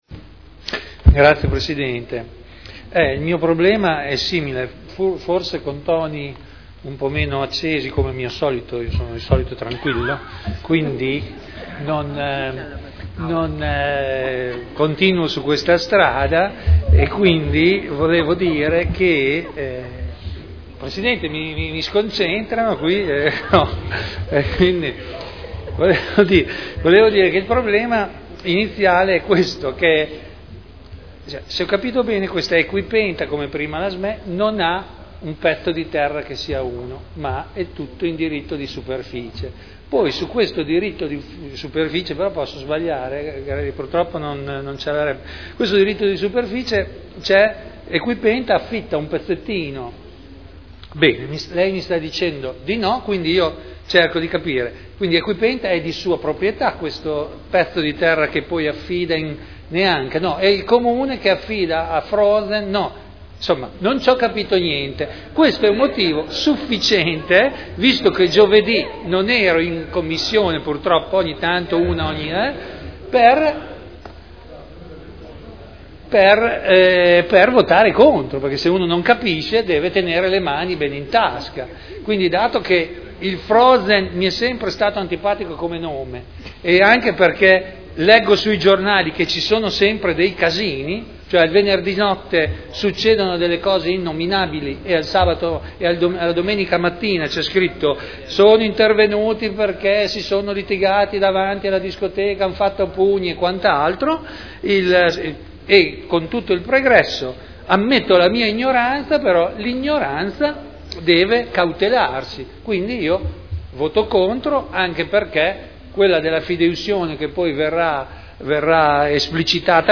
Vittorio Ballestrazzi — Sito Audio Consiglio Comunale
Seduta del 30/05/2011. Dichiarazione di voto su proposta di deliberazione: Proroga e integrazione del diritto di superficie assegnato a Equipenta Srl per sport equestri in Via Contrada